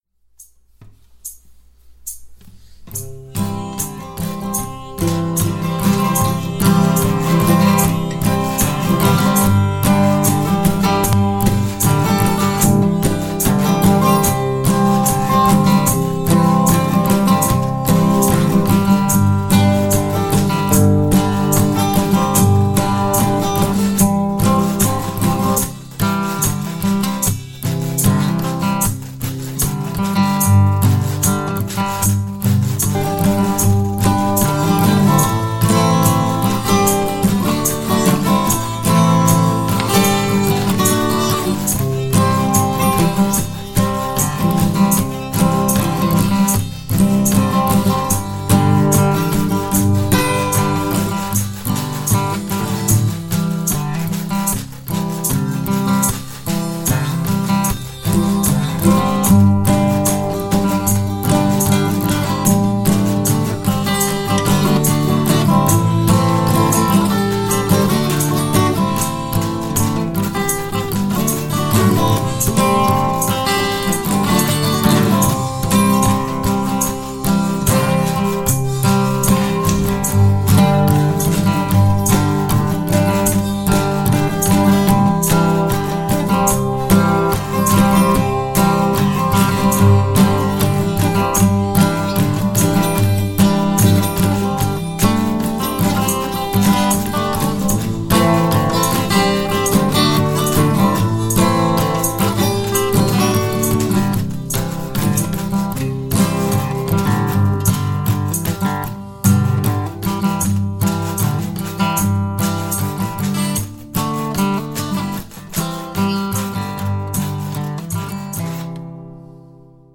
I decided to get back to my Pyscho-billie roots.
on washboard, harmonica, acoustic guitar, and tambourine.
But instead, I left this as an instrumental.